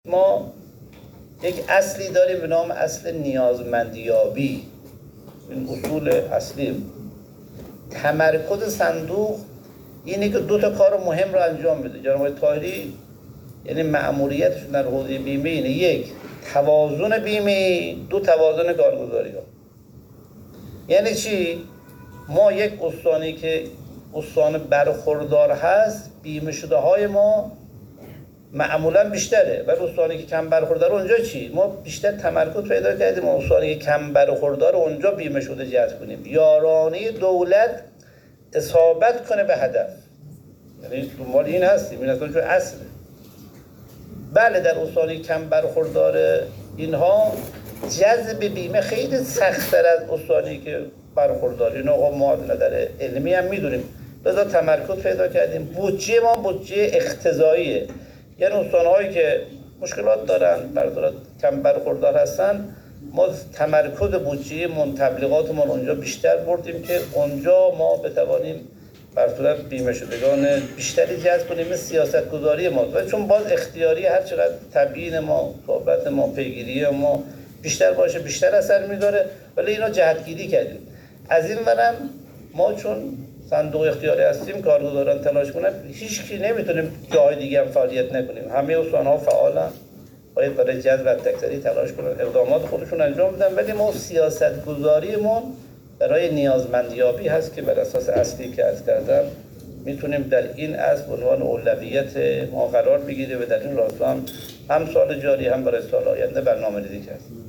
بیت‌الله برقراری مدیرعامل صندوق بیمه اجتماعی کشاورزان روستائیان و عشایر در پاسخ به سوال خبرنگار اقتصادی خبرگزاری علم و فناوری آنا مبنی بر اینکه این خدمات دهی بیمه‌ای با یارانه دولتی به کدام استان‌ها و بر چه پایه‌ای برنامه ریزی شده است، توضیحاتی داد که در ادامه می‌شنوید.